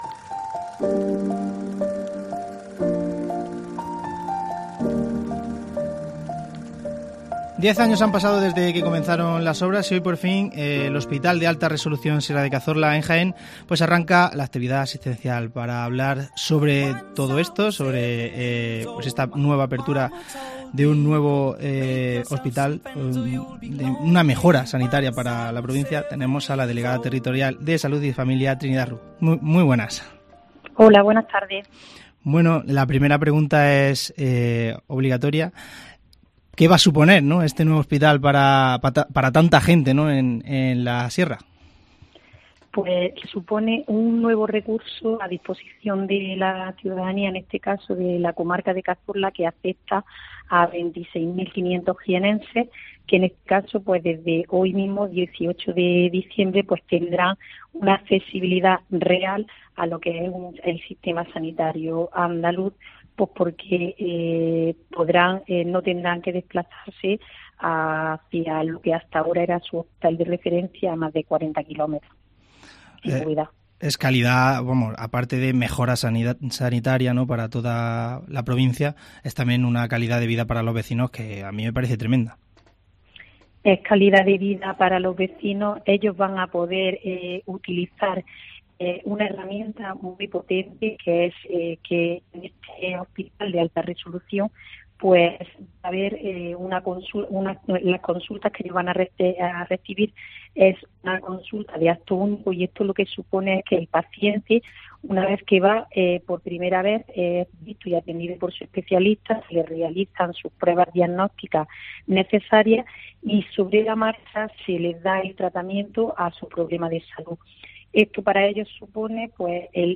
Trinidad Rus, Delegada de Salud en Jaén sobre el Hospital de Cazorla